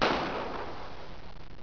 1 channel
knall2b.wav